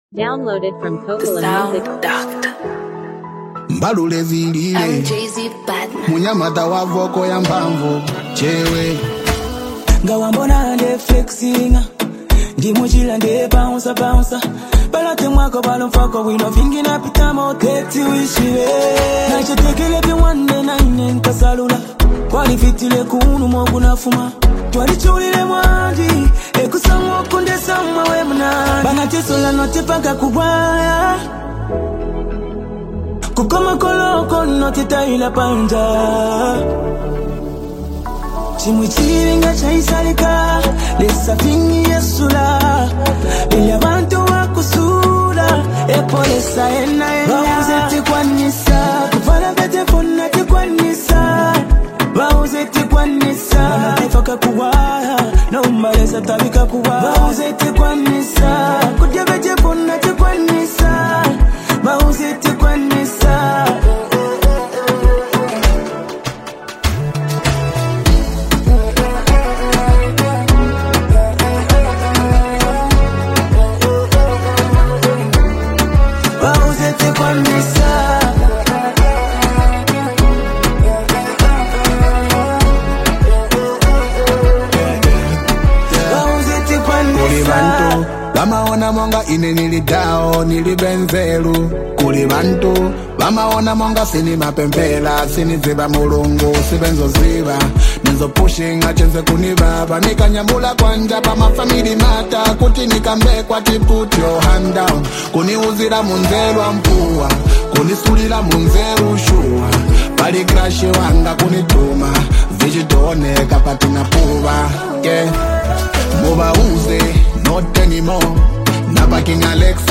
uplifting Afro-fusion single
yet he maintains a hopeful tone throughout.
emotionally rich and distinctive voice